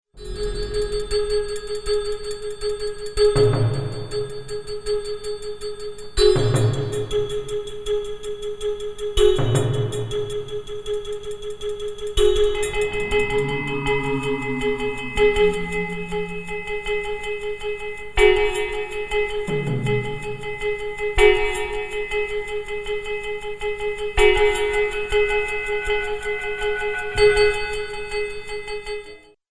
13 InstrumentalCompositions expressing various moods.